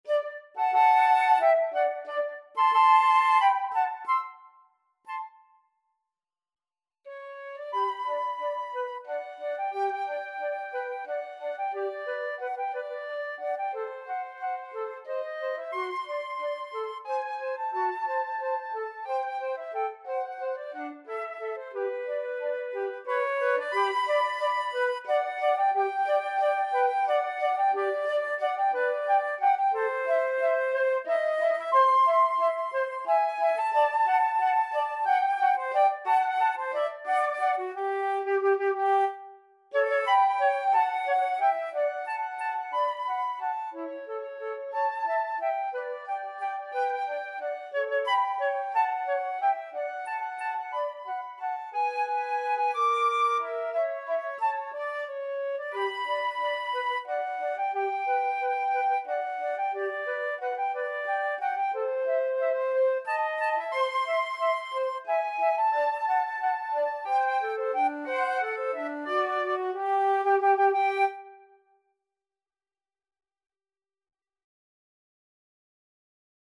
Flute 1Flute 2
3/4 (View more 3/4 Music)
~ = 180 Tempo di Valse
Classical (View more Classical Flute Duet Music)